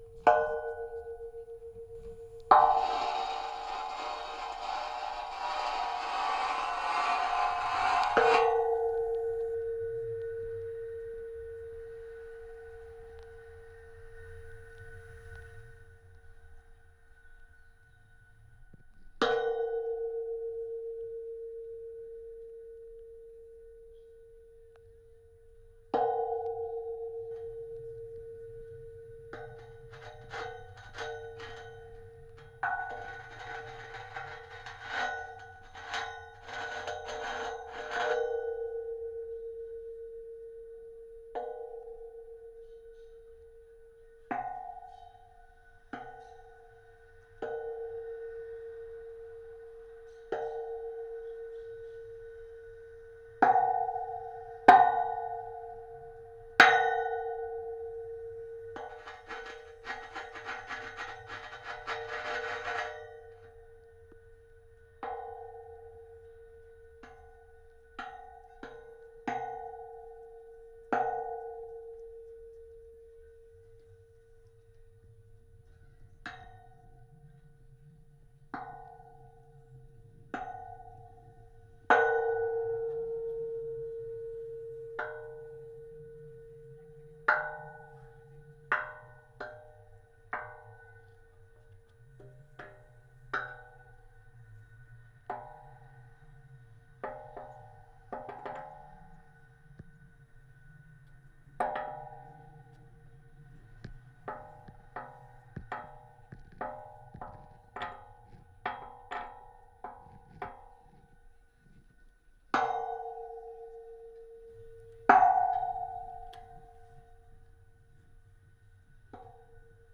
Trabajo sonoro con micros de contacto en estructura metálica en la plaza de Ramón Barnils de Sant Cugat del Vallès.
estructura-metc3a1lica.wav